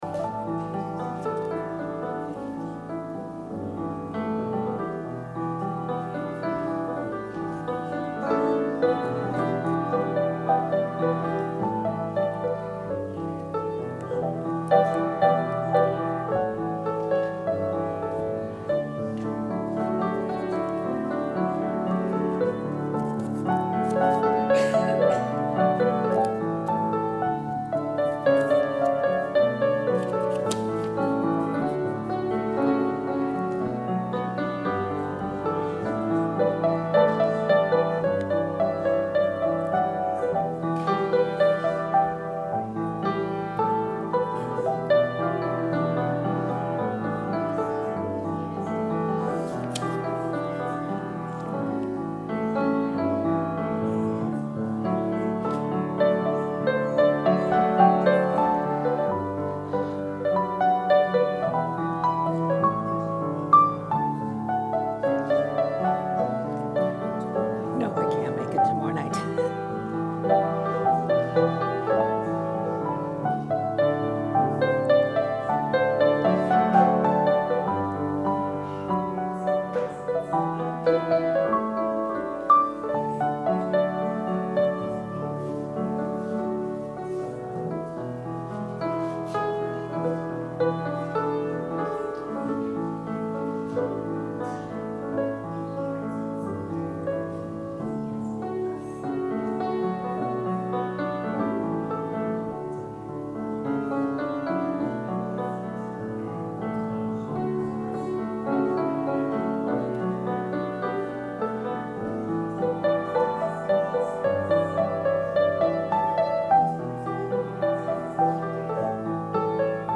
Sermon – November 3, 2019